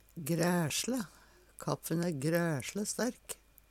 græsjle - Numedalsmål (en-US)